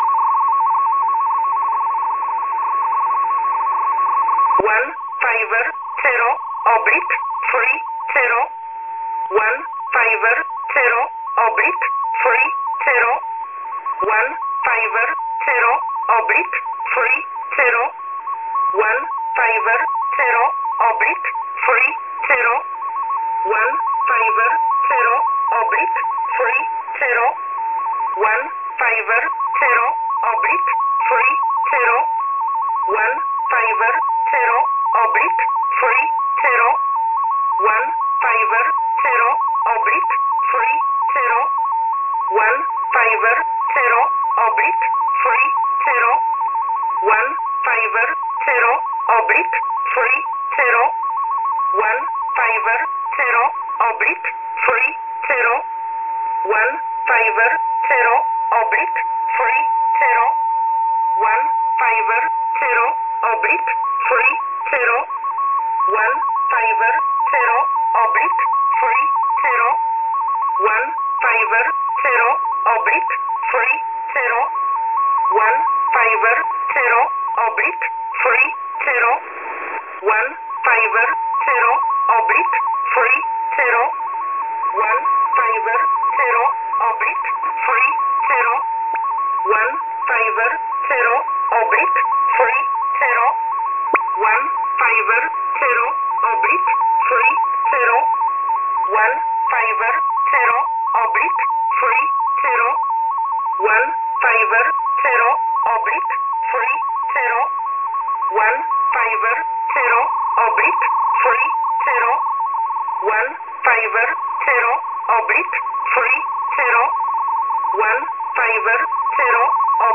Recordings of English numbers stations